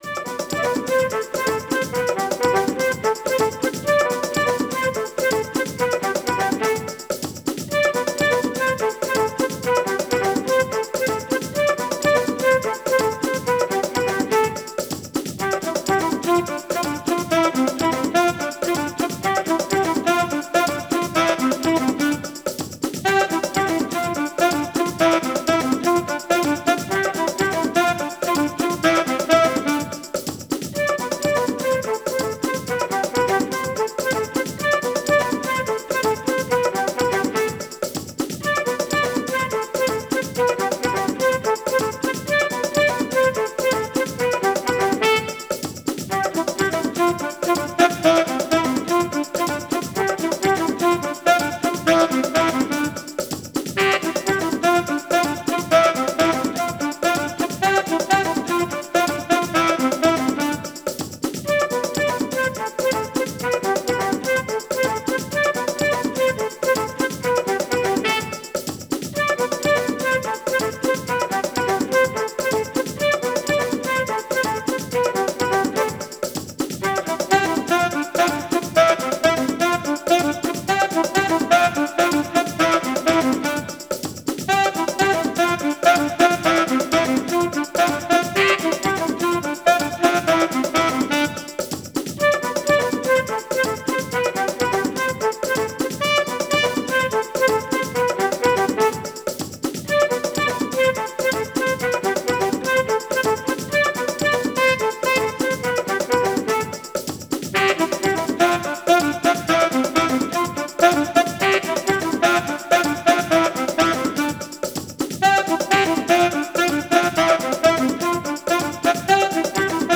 Tempo: 115 bpm / Datum: 20.04.2018